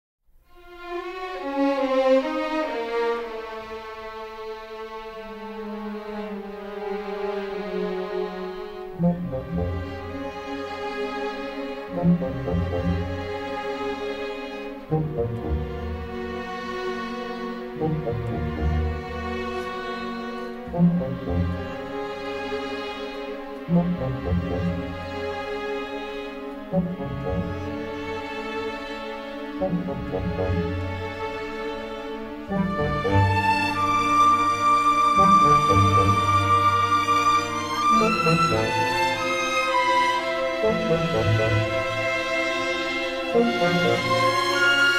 Utilizing exotic percussion and a talented flute soloist
was recorded by a non-union orchestra in Toronto